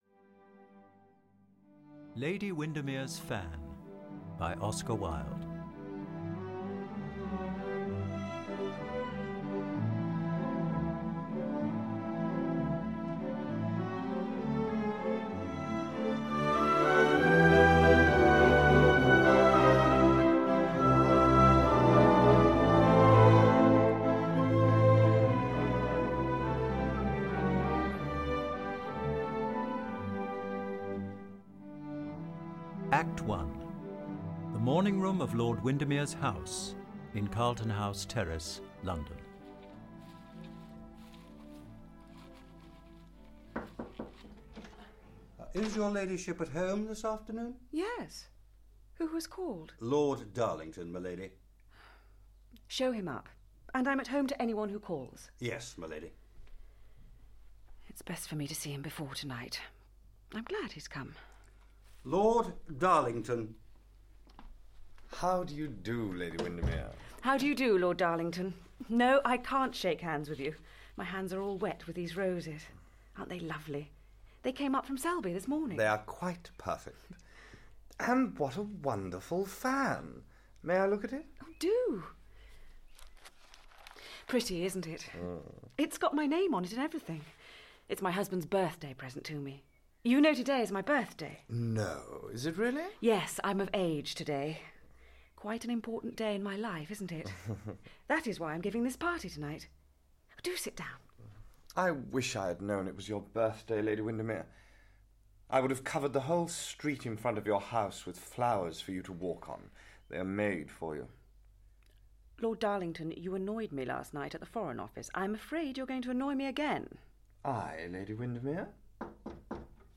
Lady Windermere’s Fan (EN) audiokniha
Ukázka z knihy
This new audiobook production brings together a cast worthy of Wilde’s creative genius.
• InterpretJuliet Stevenson, Samuel West, Emma Fielding, Michael Sheen, Sarah Badel